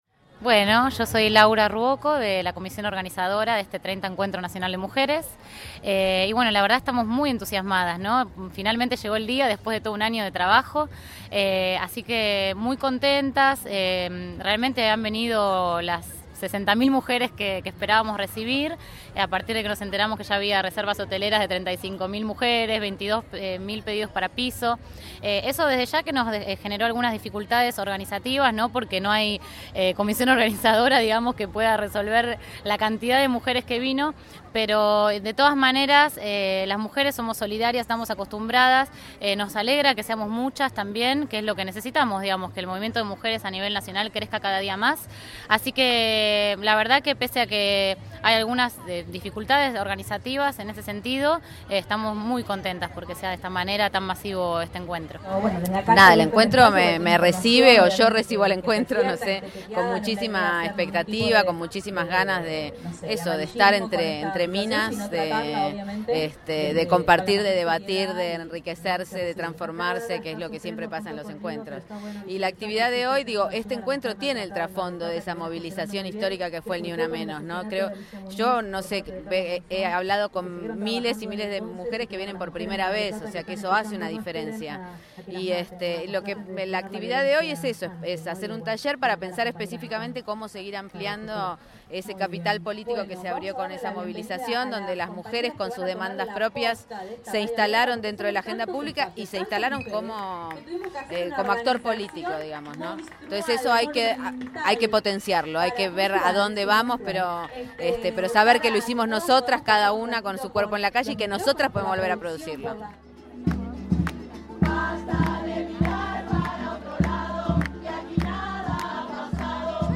Testimonios recogidos durante los talleres, Cánticos cantados en la marcha del Encuentro